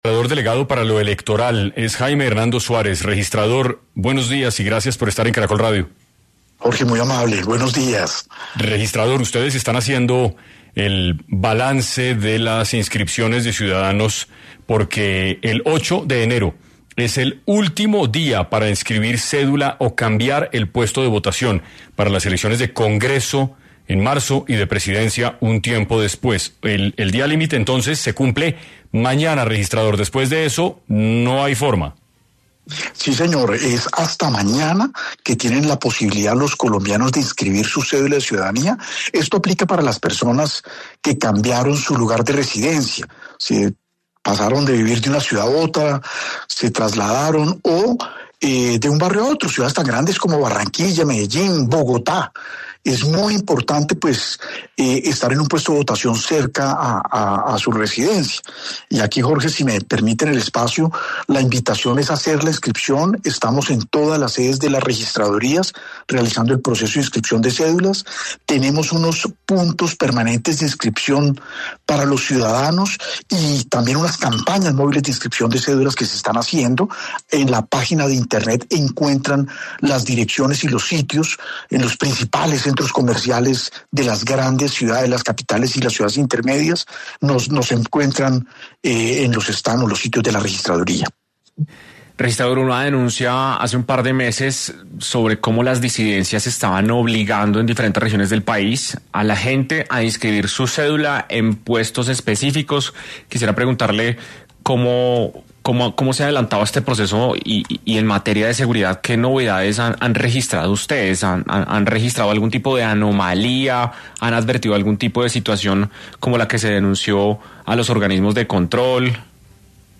El registrador delegado, Jaime Hernando Suárez, habló en 6 AM de Caracol Radio, y mencionó algunos detalles acerca de la fecha límite para cambiar el lugar de votación en dado caso de que la persona haya cambiado de vivienda, ciudad o región.